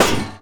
metal_solid_hard2.wav